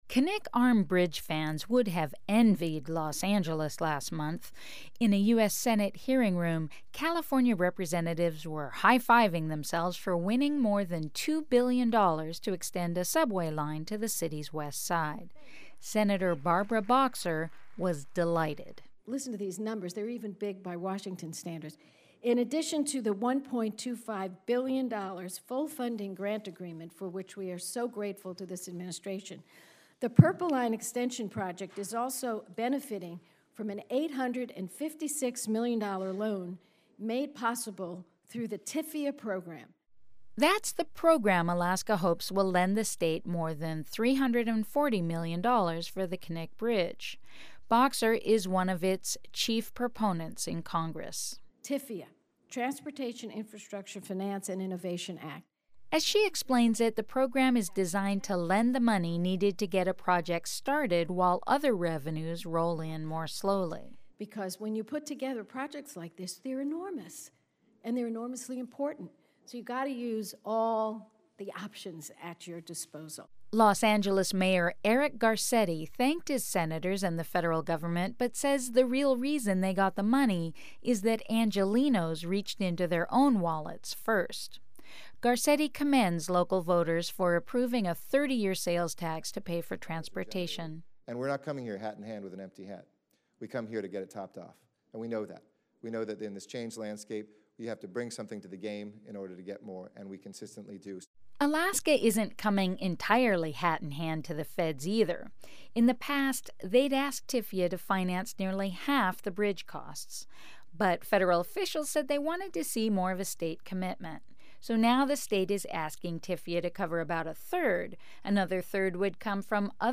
In a U.S. Senate hearing room, California representatives were high-fiving themselves for winning more than $2 billion to extend a subway to the city’s Westside.